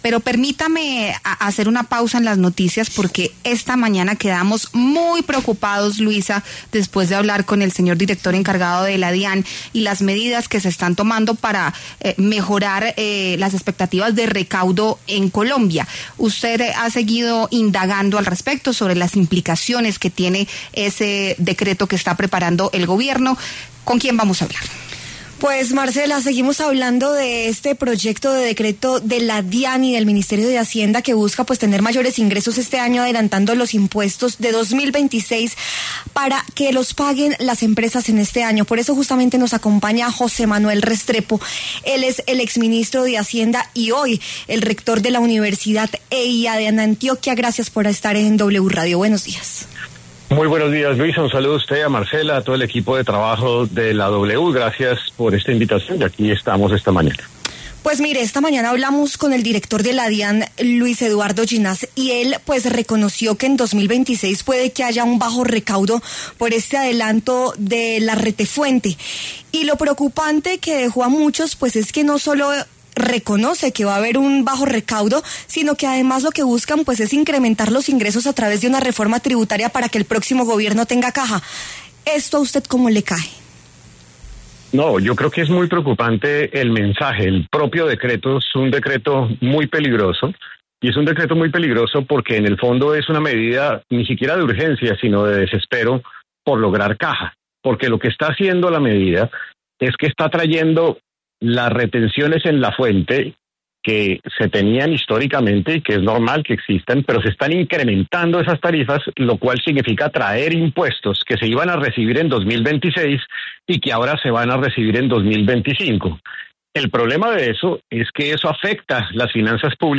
El exministro de Hacienda José Manuel Restrepo pasó por los micrófonos de La W para hablar sobre el decreto que alista el Gobierno Nacional para aumentar la retención en la fuente para empresas.